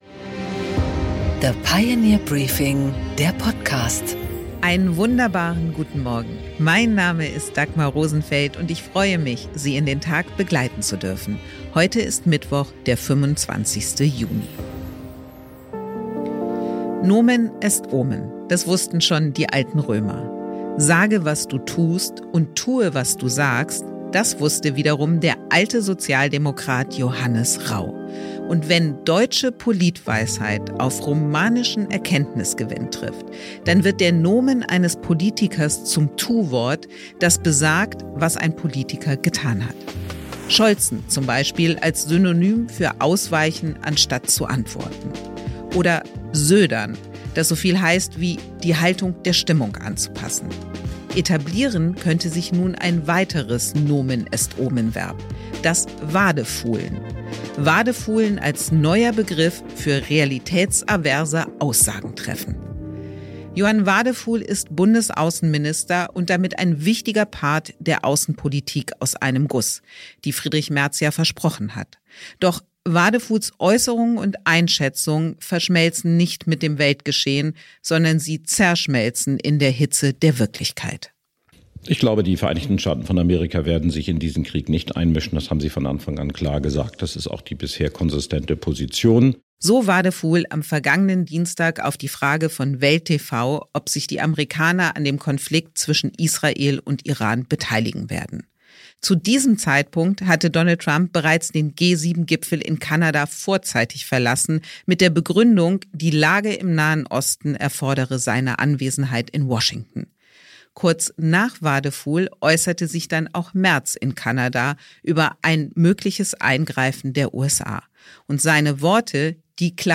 Interview mit Peer Steinbrück